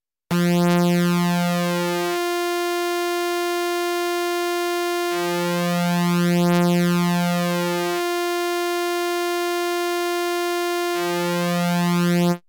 Modulating the PW of a saw-wave with an LFO would make it rock gentley from one octave to the next as the middle-transient of the waveform cycle moves away from its 50%:50% pulse-width ratio.
An example of what a single sawtooth oscillator with pulse-width modulation sounds like:-
Pulse-width modulated by LFO]